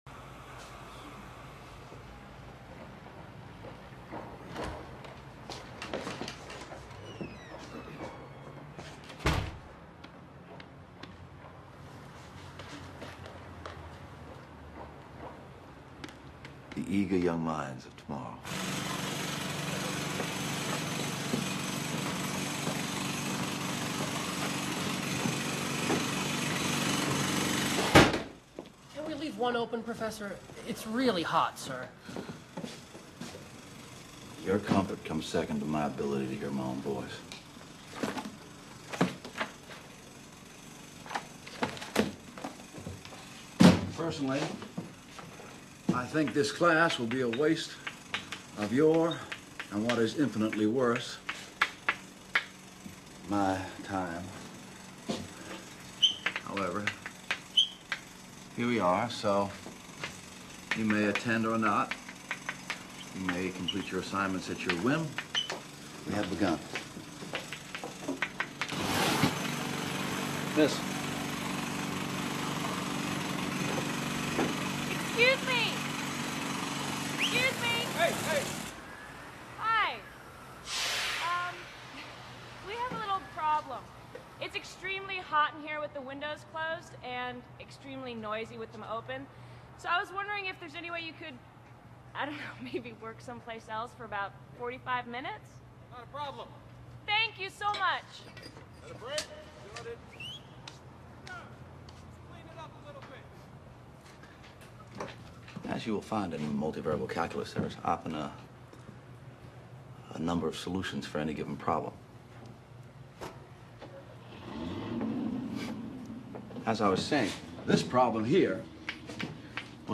Mathematician John Nash (Russel Crow) gives his first multivariable calculus lesson at MIT around 1951. He throws the book into the garbage and presents a problem which is above the level of a beginning multivariable calculus student.